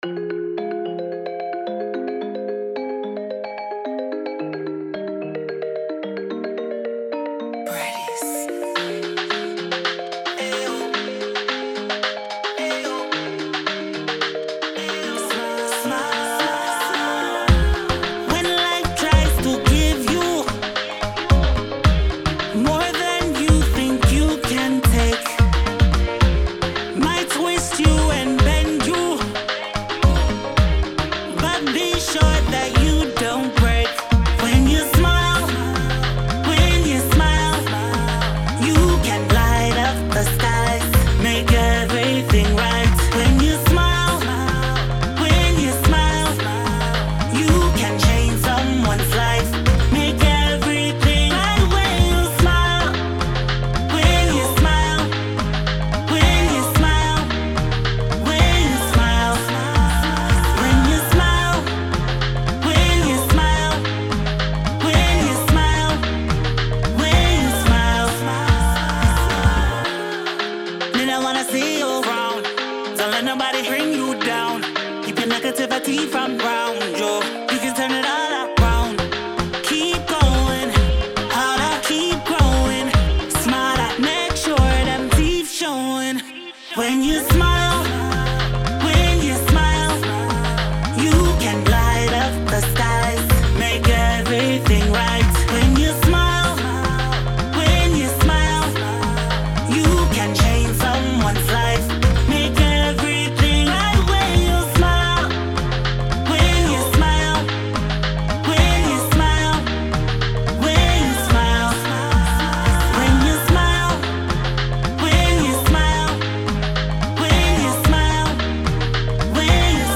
Caribbean Reggae-Pop singer and actress